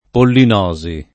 [ pollin 0@ i ]